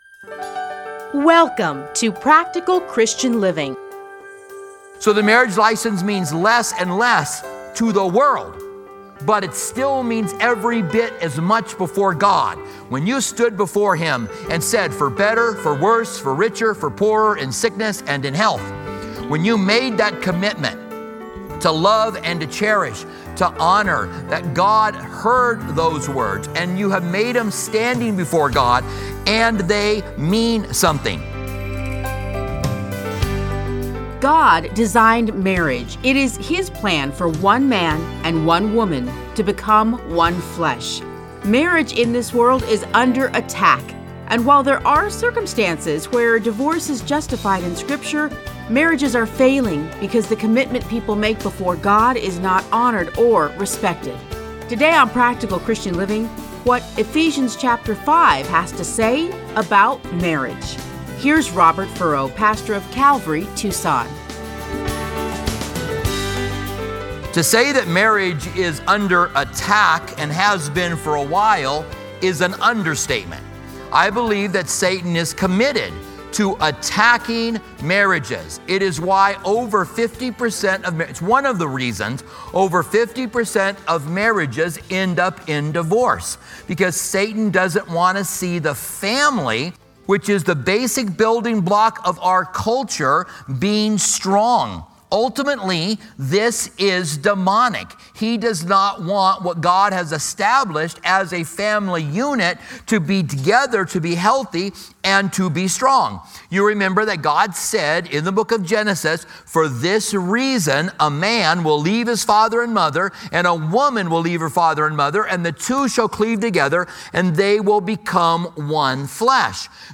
Listen to a teaching from Ephesians 5:22-33.